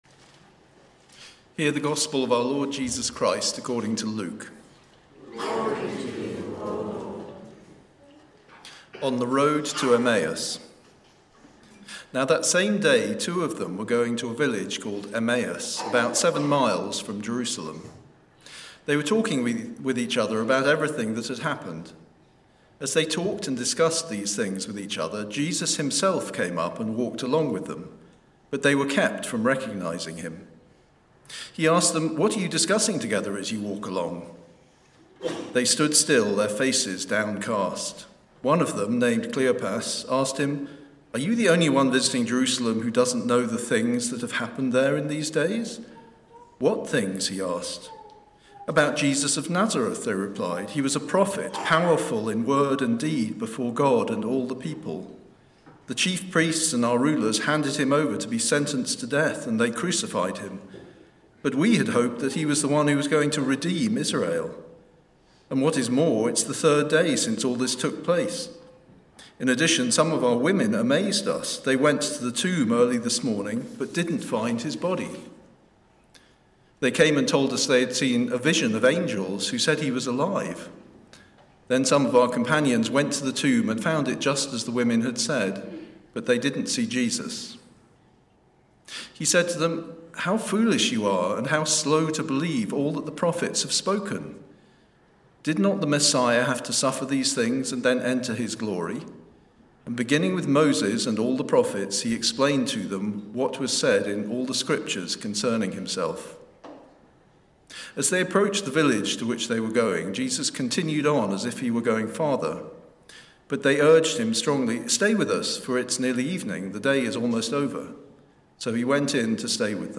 TCE-Sermon-19_4_2026-The-Road-to-Emmaus.mp3